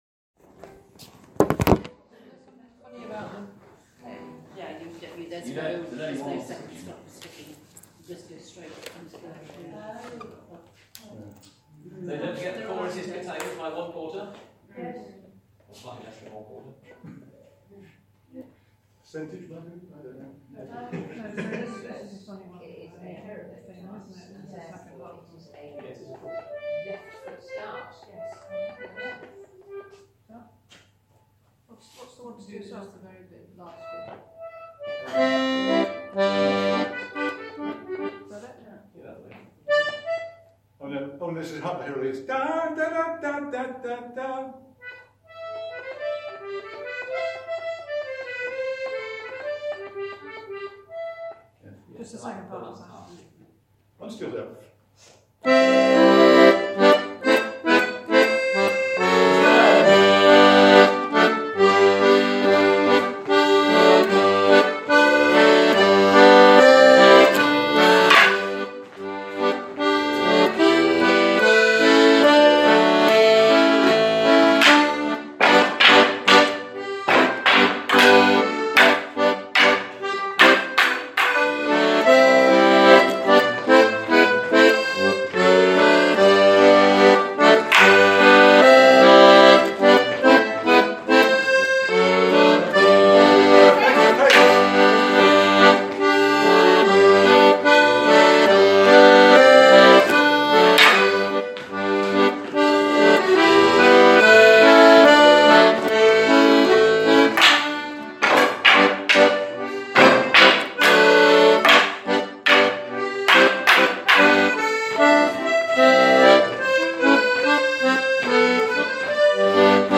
• Melodeons (D/G)
• Fiddles
At the beginning of 2019 we embarked on a project of recording our practices so that the tunes can be used for personal music practice purposes.